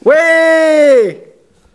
ajout des sons enregistrés à l'afk ... Les sons ont été découpés en morceaux exploitables. 2017-04-10 17:58:57 +02:00 304 KiB Raw History